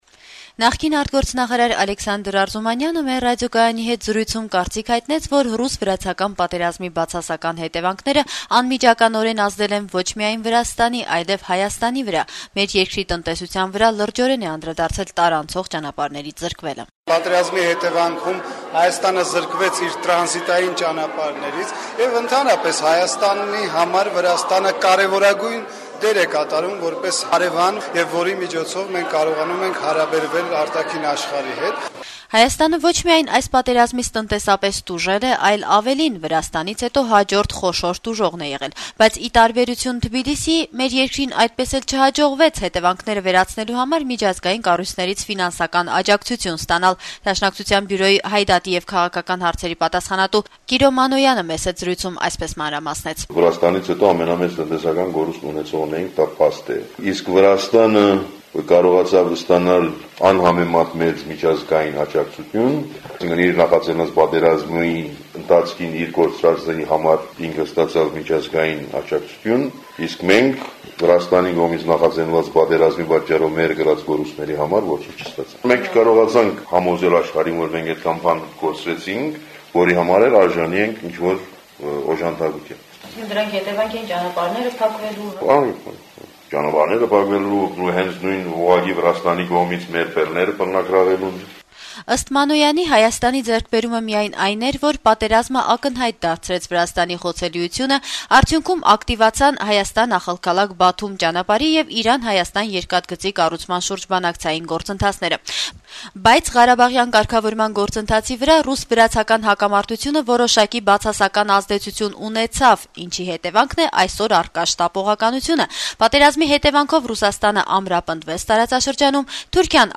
Հայաստանի վրա անցած տարվա օգոստոսին ռուս-վրացական կարճատեւ պատերազմի ազդեցություն մասին «Ազատություն» ռադիոկայանի հետ զրույցներում ուրբաթ օրը իրենց կարծիքներն են հնչեցրել հայաստանյան տարբեր քաղաքական ուժերի ներկայացուցիչները: